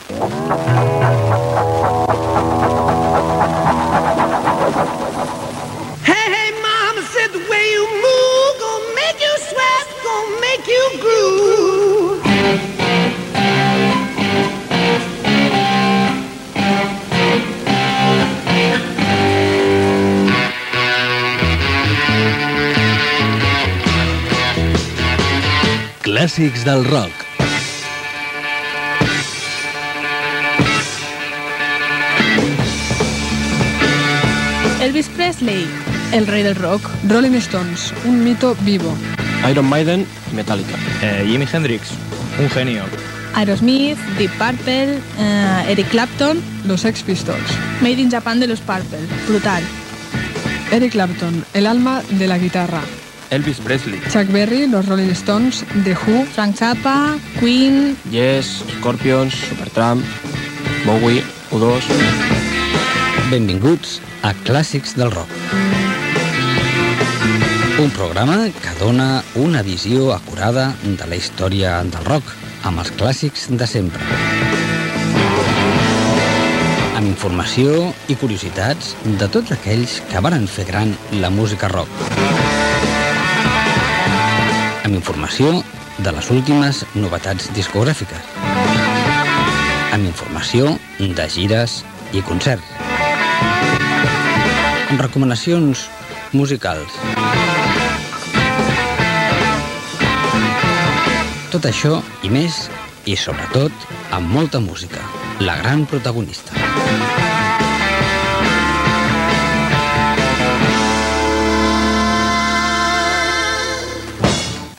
Careta del programa i salutació amb els continguts de l'espai.
Musical